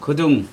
[kuduŋ] noun ear